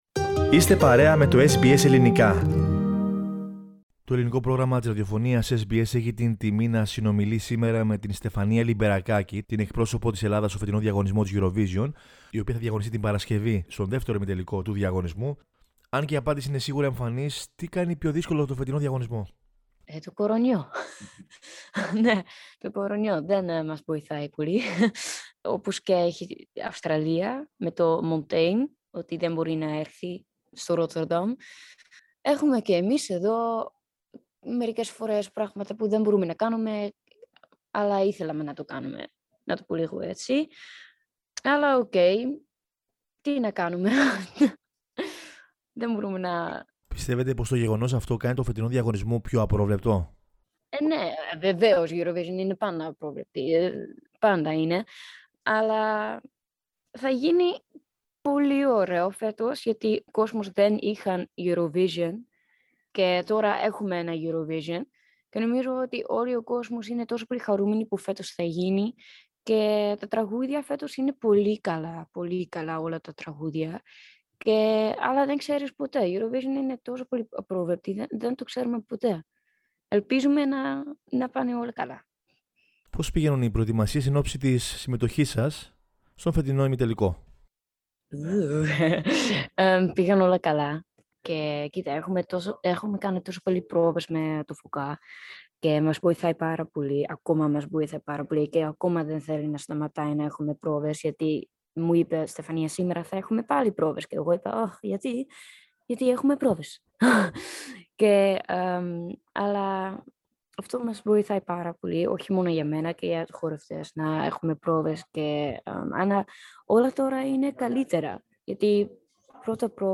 Κύρια Σημεία H ελληνική καταγωγή και οι συχνές επισκέψεις στην Ελλάδα Τα αγαπημένα ελληνικά τραγούδια και καλλιτέχνες Η πρώτη "γεύση" από τον διαγωνισμό στη Eurovision Junior Η ομογενής τραγουδίστρια μίλησε στο Ελληνικό Πρόγραμμα της Ραδιοφωνίας SBS για τη συμμετοχή της αυτή, τις δυσκολίες που παρουσιάζονται εξαιτίας της νόσου COVID-19, τα μουσικά ακούσματά της, καθώς και την ελληνική της καταγωγή.